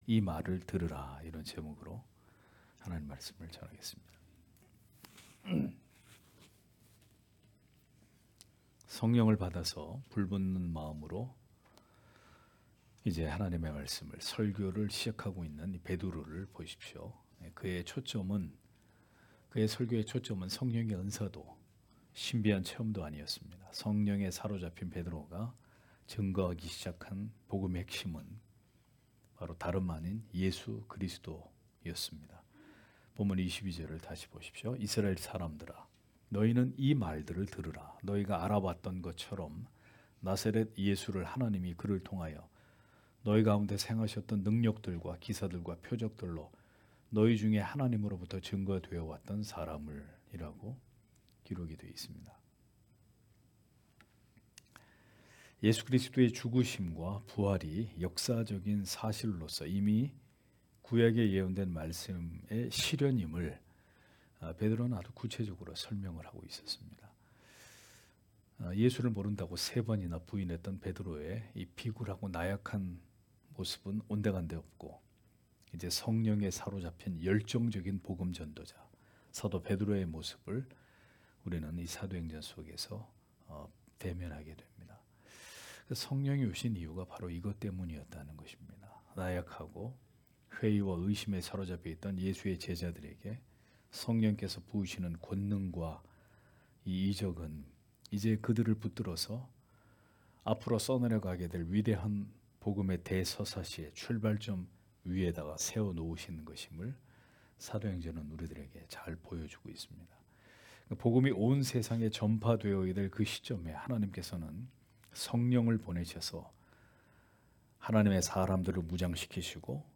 금요기도회 - [사도행전 강해 13] 이 말을 들으라 (행 2장 22- 24절)